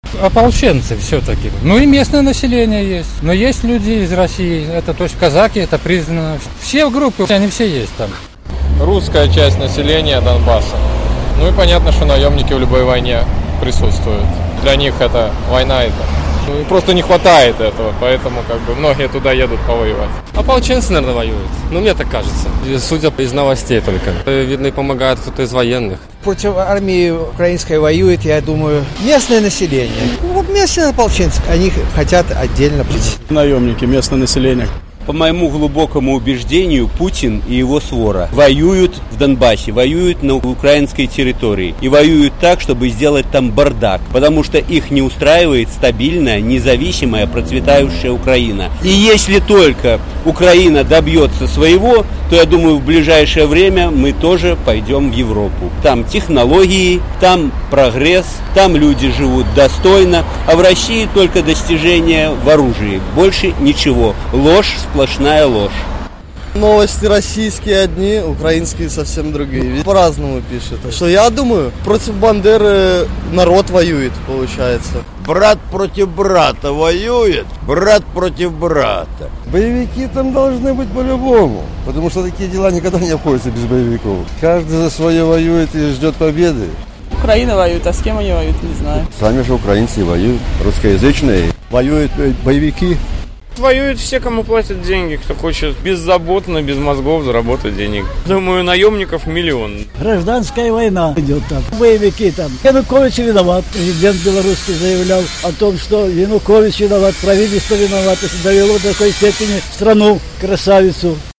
У Данбасе ваююць усе, каму плацяць грошы, апытаньне ў Гомелі
На гэтае пытаньне адказваюць жыхары Гомеля.